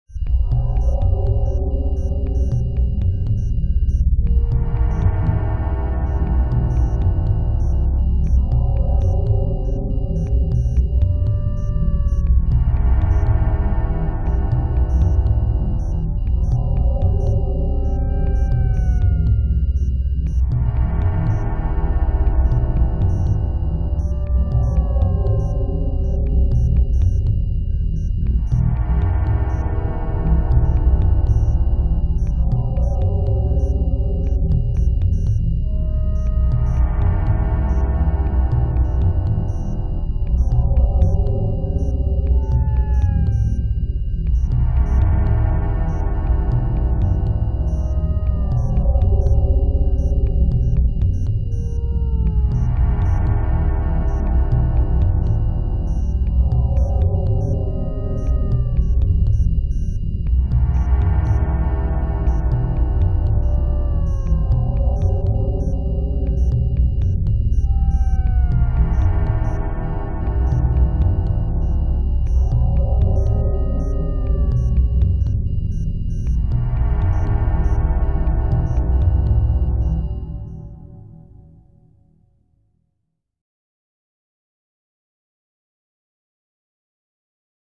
horror